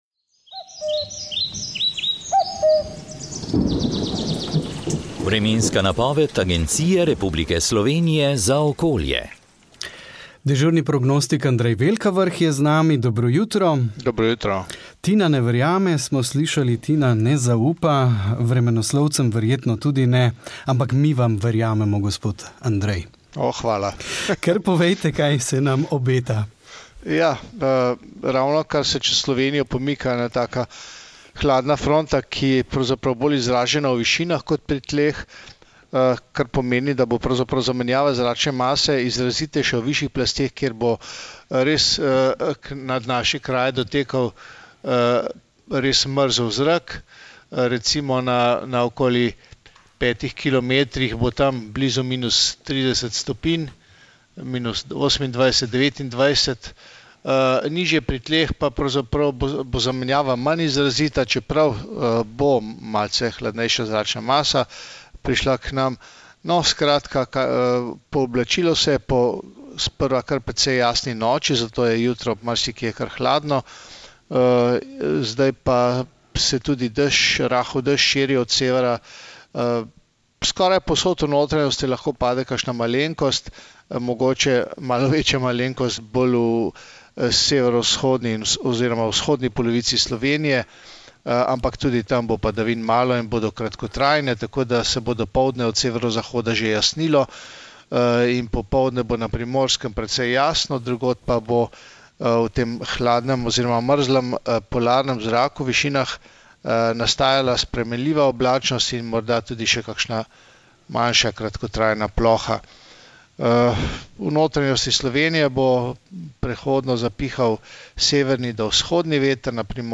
Vremenska napoved 13. oktober 2021